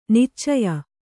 ♪ niccaya